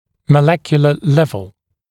[mə’lekjulə ‘levl][мэ’лэкйулэ ‘лэвл]молекулярный уровень